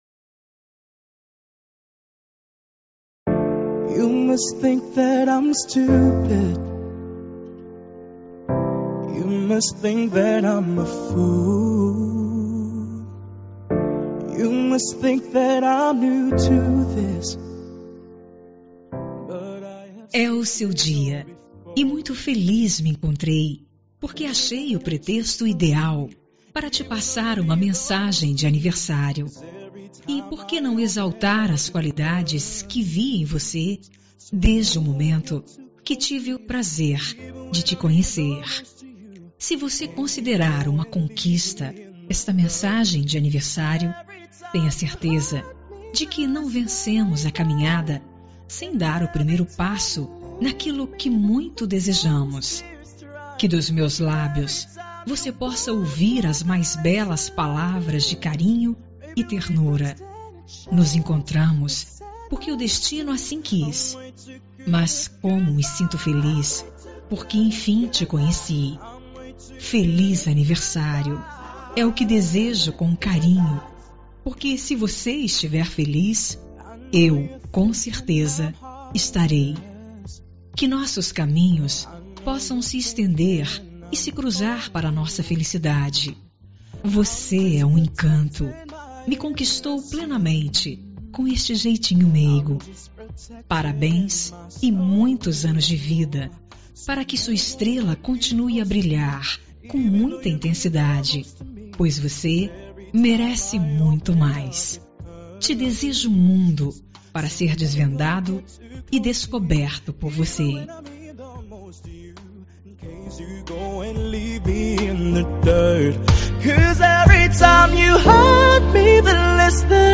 Telemensagem de Aniversário Romântico – Voz Feminina – Cód: 202120 – Suave